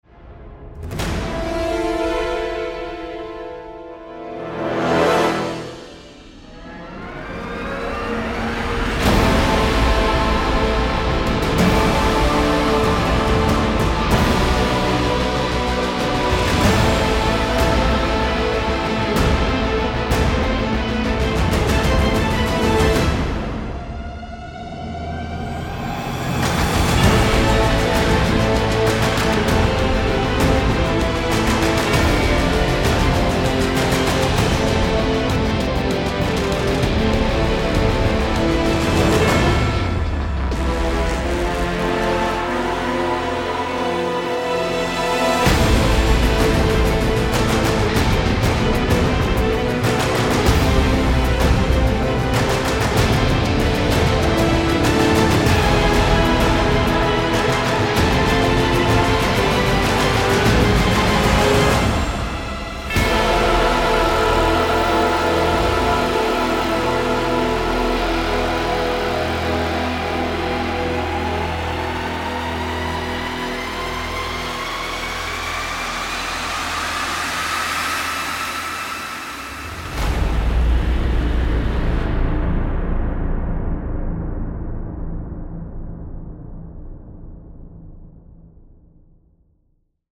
original score
full of comedic fun and heroic excitement